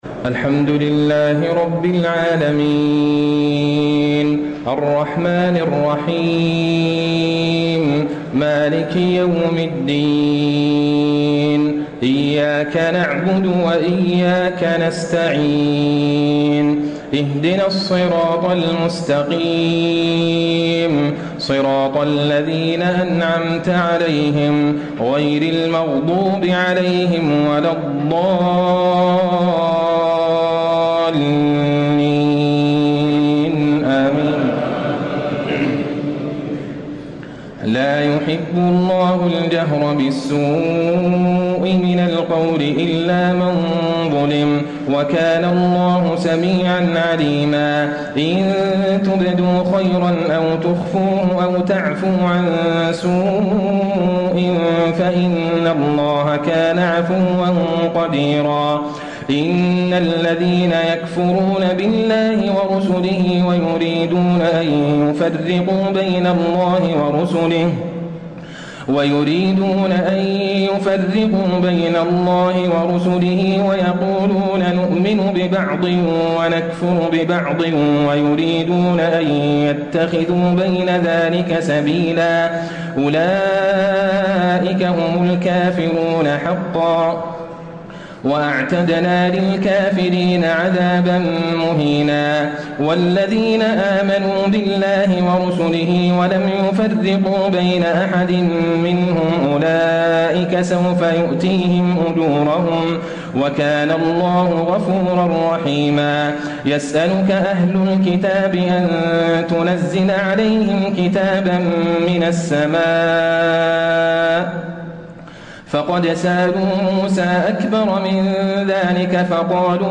تهجد ليلة 26 رمضان 1434هـ من سورتي النساء (148-176) و المائدة (1-40) Tahajjud 26 st night Ramadan 1434H from Surah An-Nisaa and AlMa'idah > تراويح الحرم النبوي عام 1434 🕌 > التراويح - تلاوات الحرمين